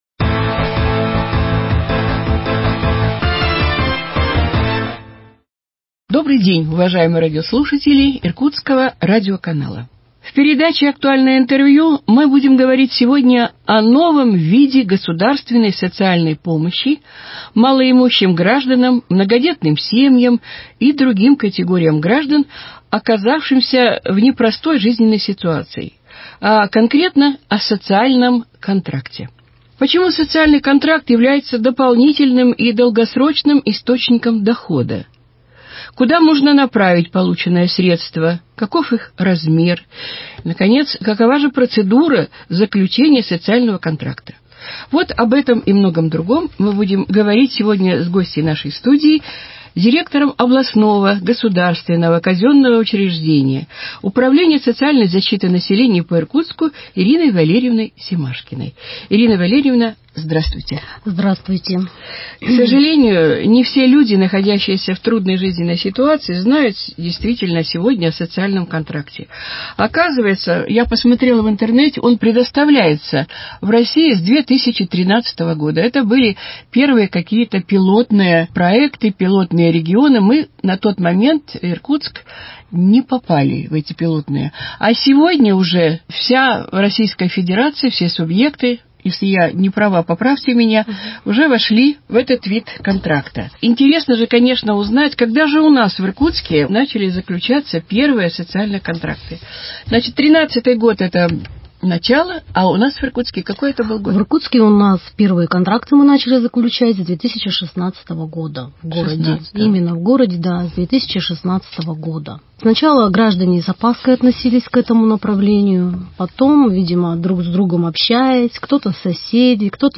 Актуальное интервью: Социальный контракт 27.04.2021